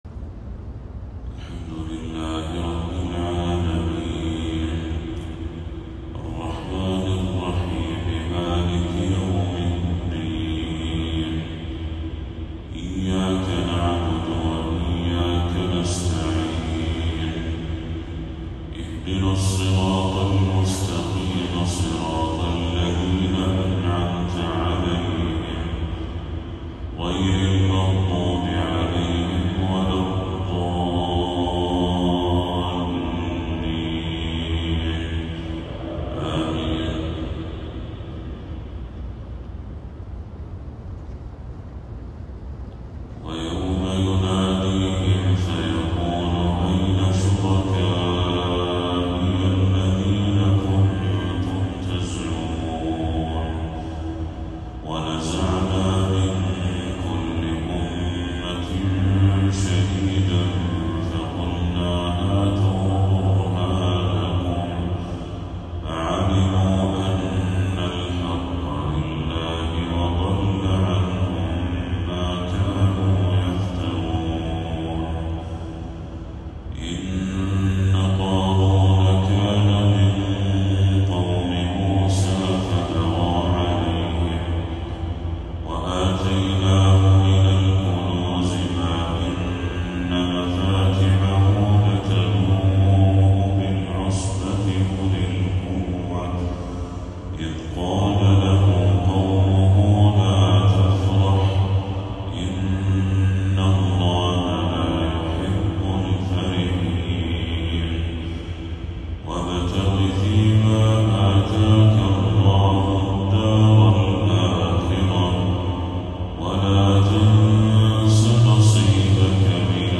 تلاوة هادئة لخواتيم سورة القصص للشيخ بدر التركي | فجر 23 صفر 1446هـ > 1446هـ > تلاوات الشيخ بدر التركي > المزيد - تلاوات الحرمين